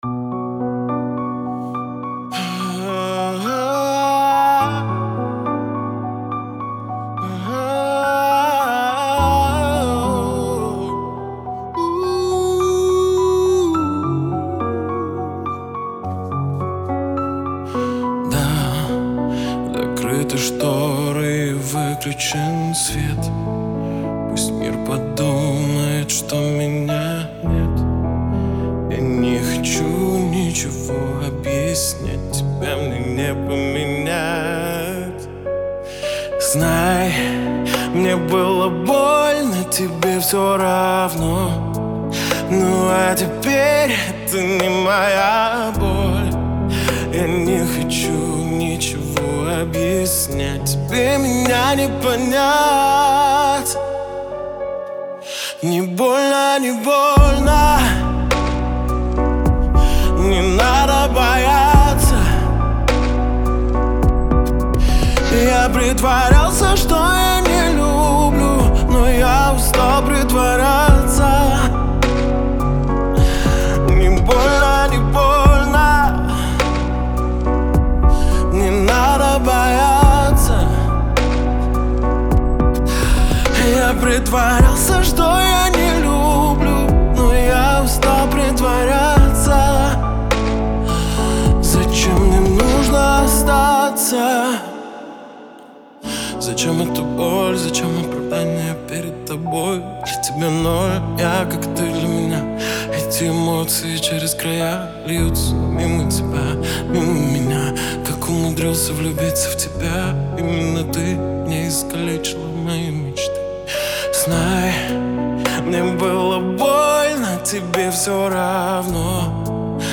Трек размещён в разделе Русские песни / Поп / 2022.